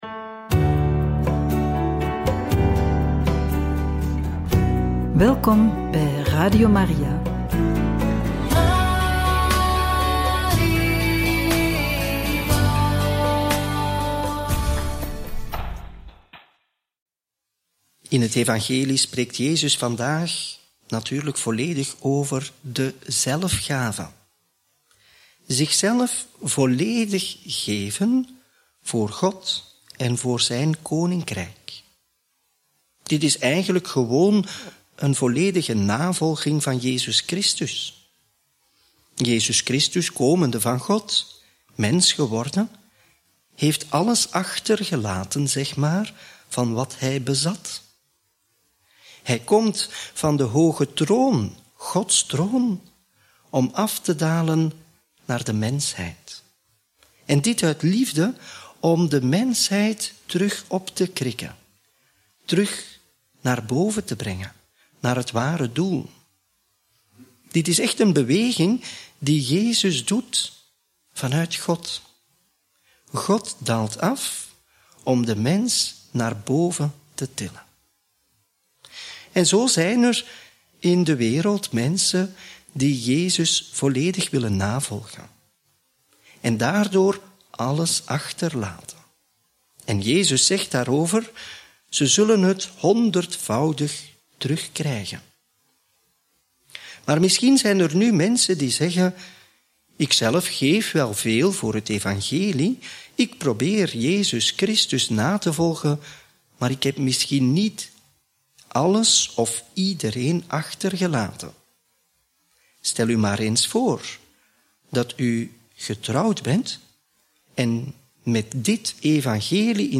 Homilie bij het Evangelie op donderdag 11 juli 2024 (Mt. 10, 7-15 )